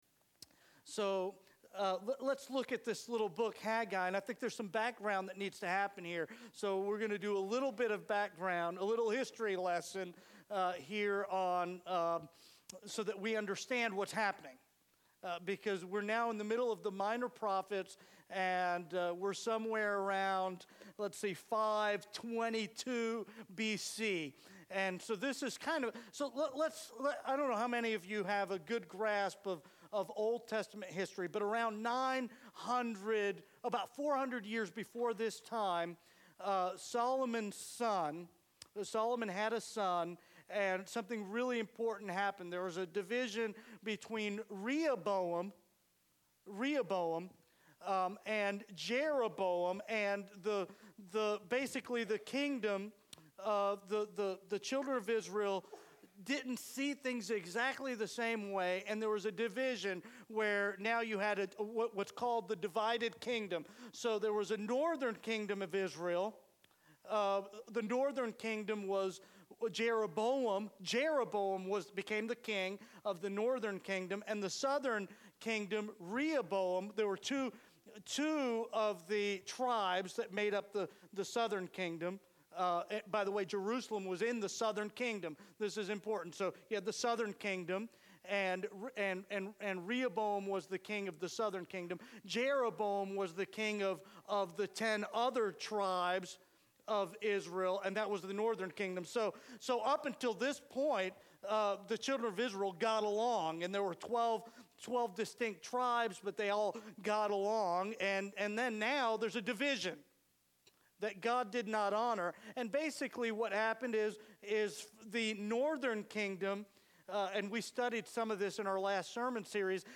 Sermons - United Baptist Church